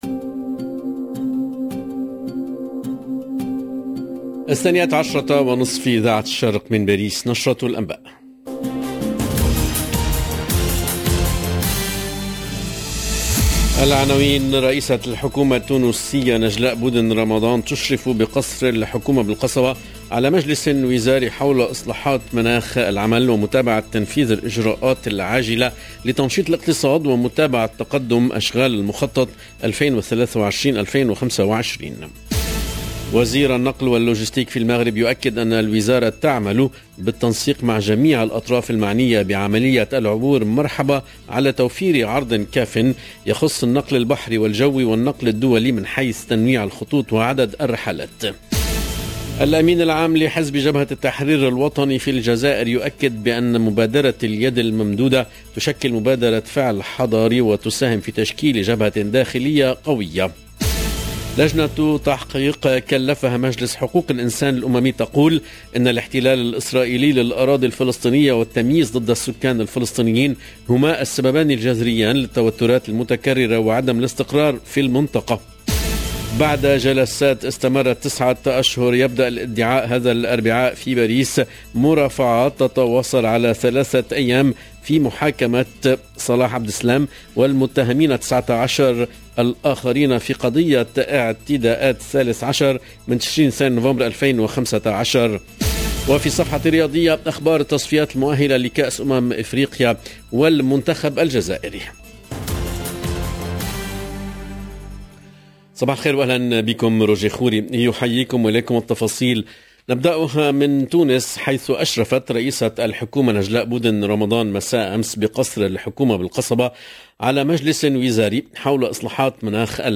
LE JOURNAL DE LA MI JOURNEE EN LANGUE ARABE DU 8/06/22